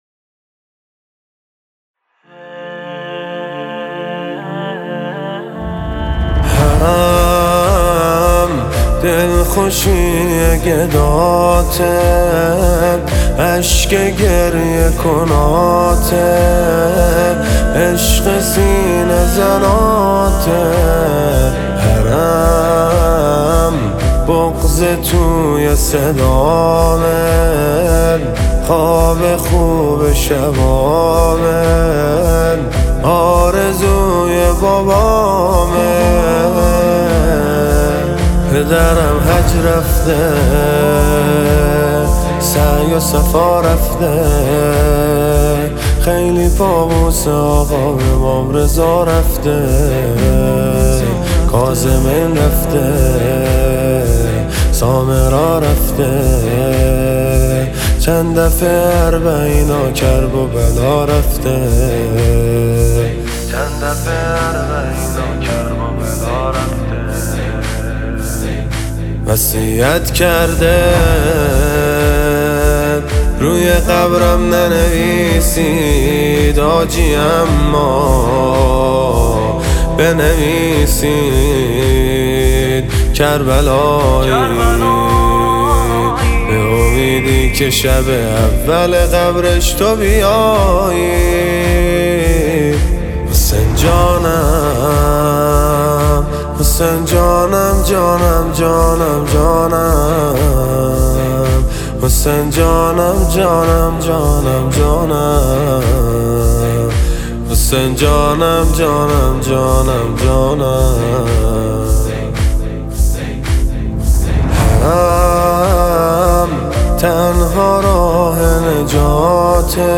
• نوحه و مداحی